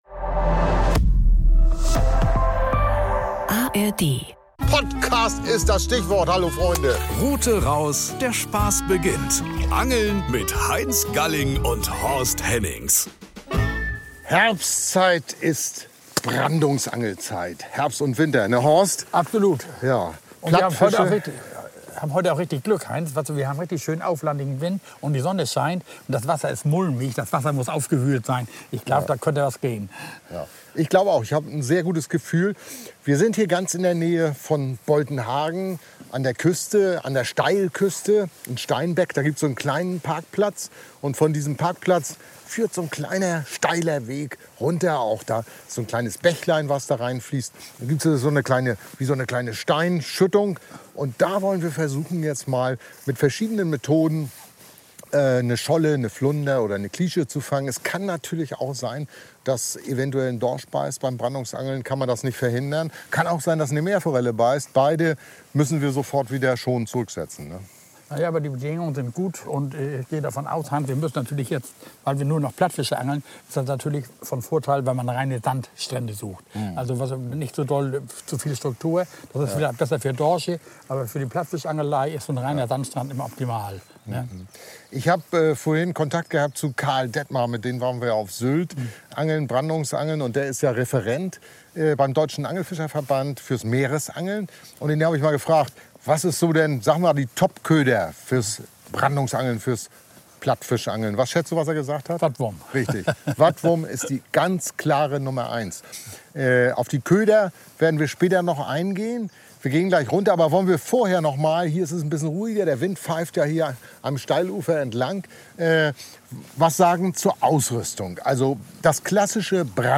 sind an der Steilküste bei Boltenhagen unterwegs und erklären wie Brandungsangeln funktioniert, welche Rute man nimmt, welche Schnur und welche Köder die besten sind.